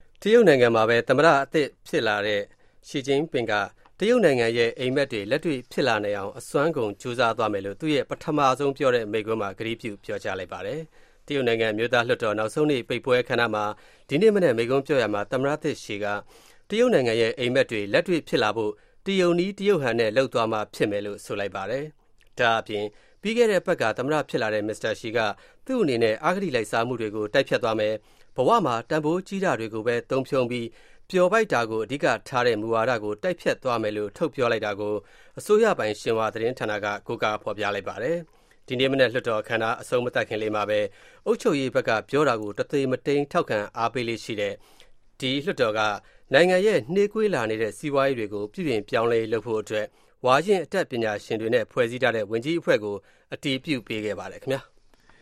China President speech